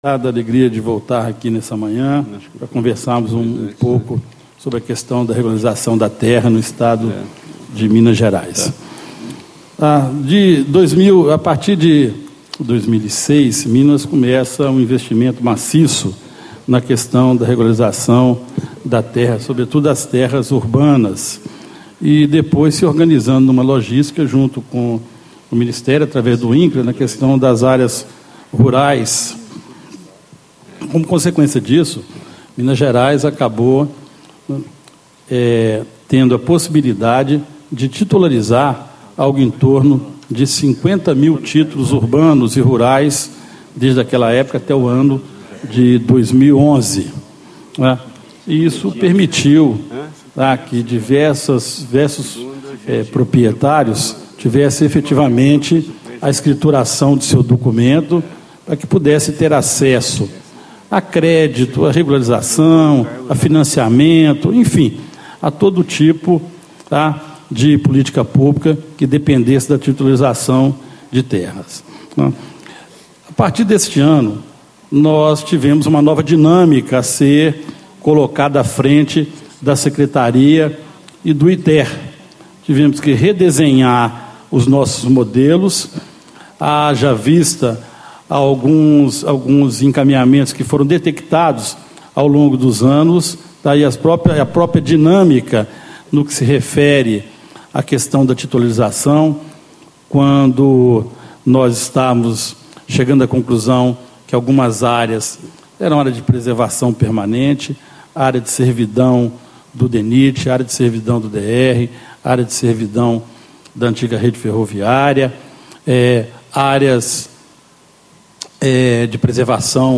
Deputado Wander Borges, Secretário de Estado Extraordinário de Regularização Fundiária de Minas Gerais. Painel: Acesso à Terra e Regularização Fundiária